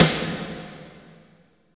52.08 SNR.wav